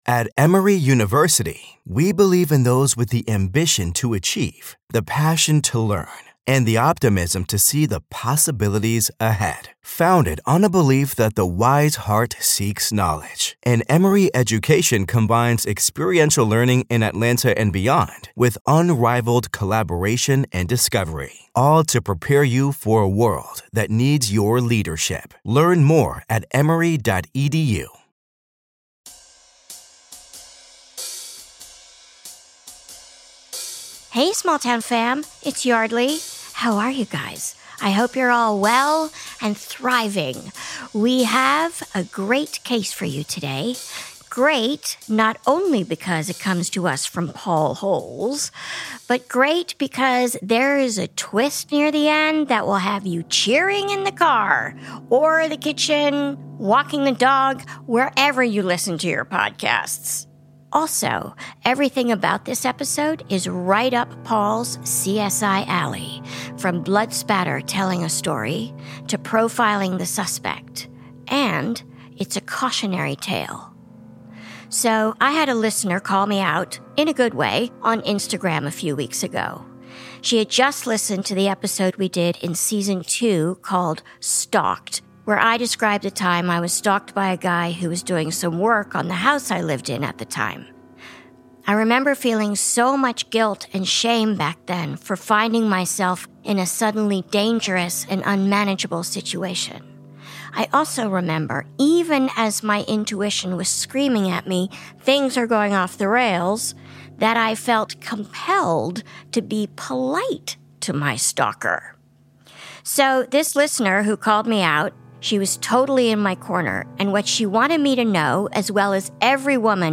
Guest: Forensic Specialist Paul Holes